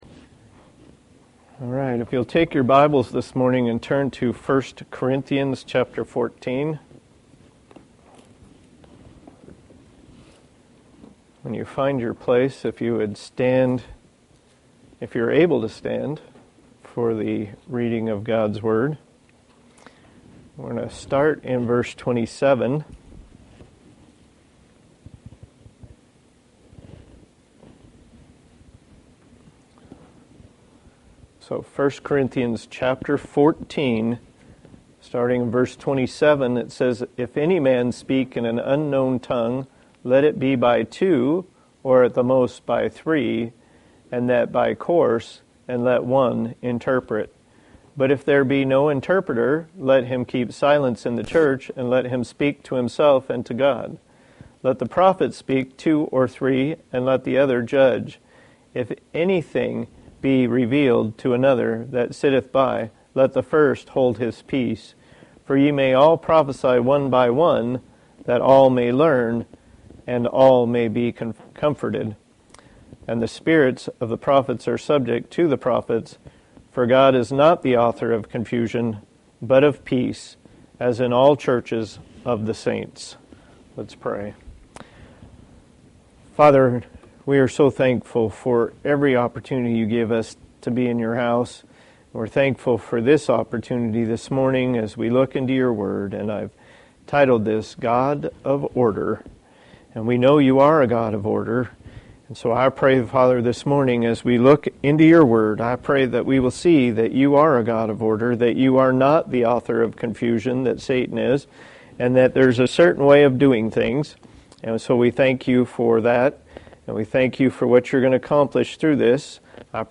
Passage: I Corinthians 14:27-33 Service Type: Sunday Morning